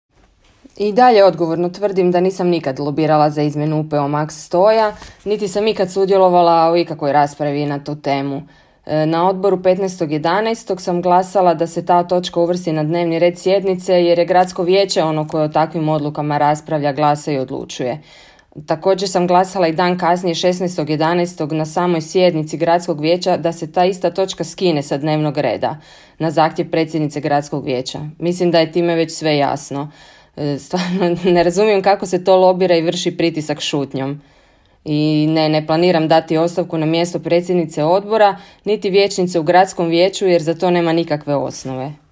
Izjava Ivana Mohorović